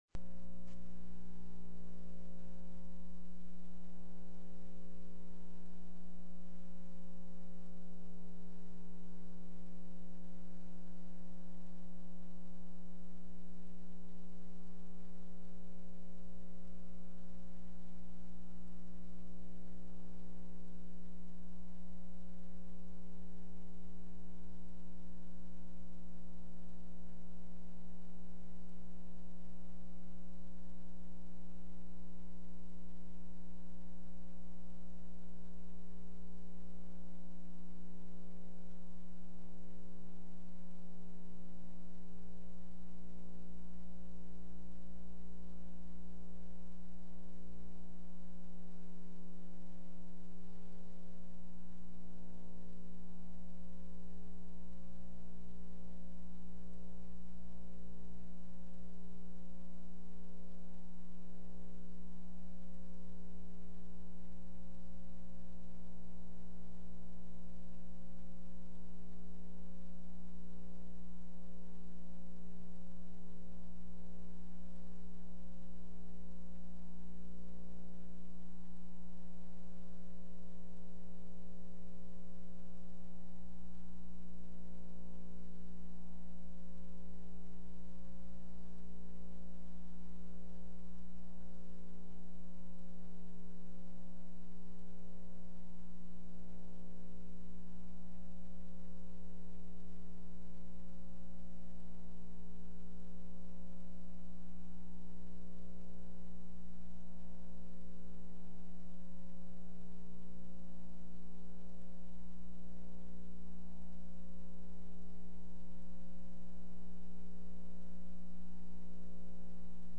-- Teleconference by invitation --